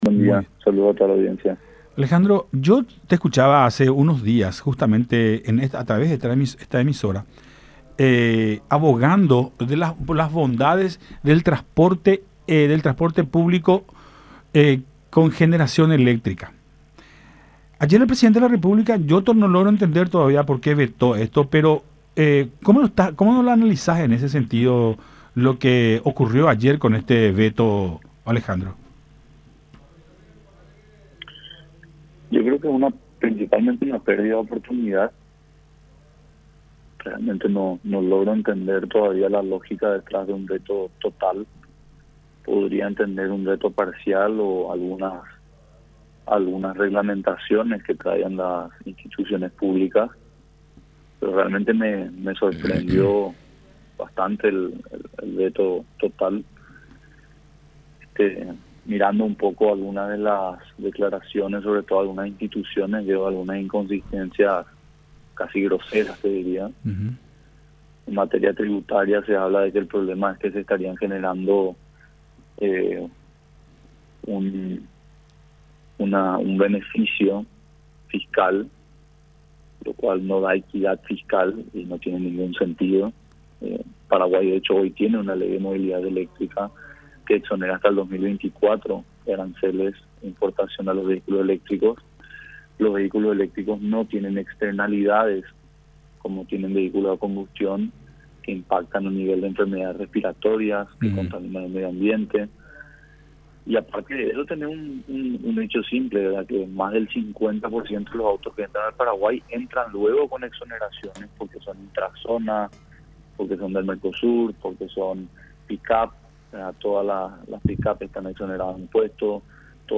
en contacto con Nuestra Mañana por Unión TV